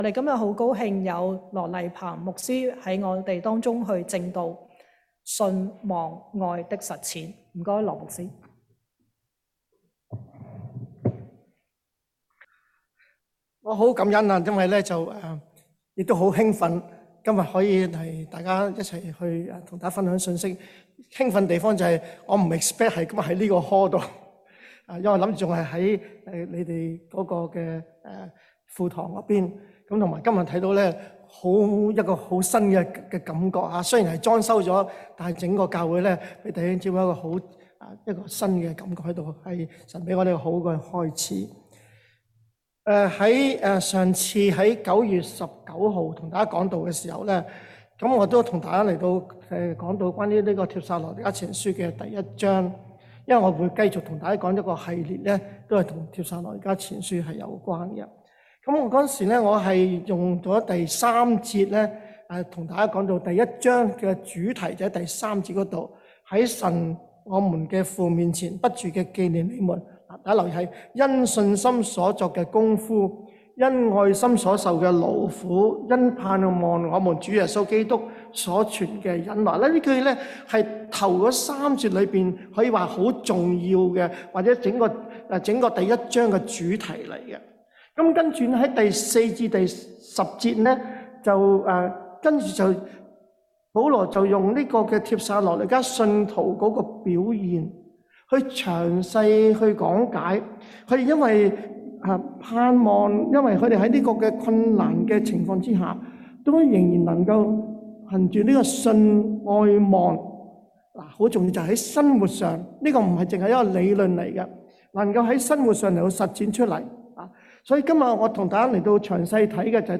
sermon1128.mp3